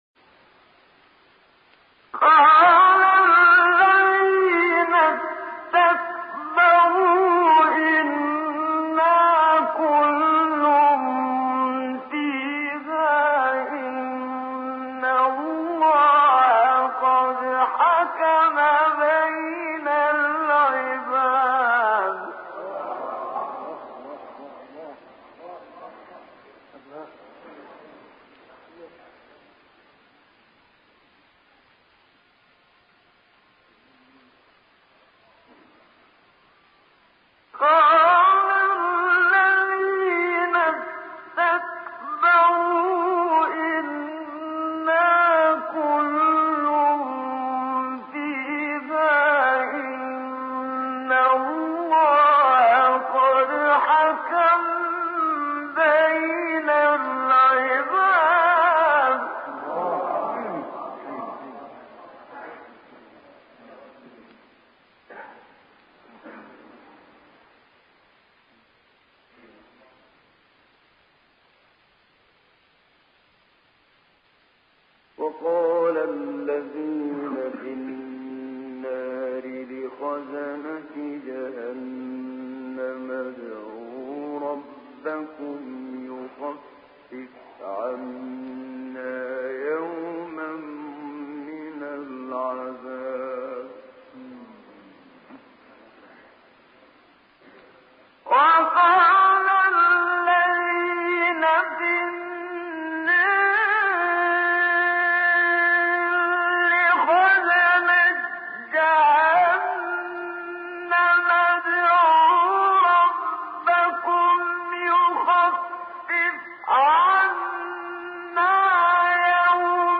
منشاوی این آیه را برای نخستین بار بم‌خوانی کرده که نشان می‌دهد این‌ها به قدری گردن‌کش اند که حاضر نیستند این درخواست را هم به درستی و با یک اضطرار و خواهش از نگهبان دوزخ داشته باشند، بلکه خیلی معمولی می‌گویند از خدایتان بخواهید تا در عذاب ما تخفیف بدهد.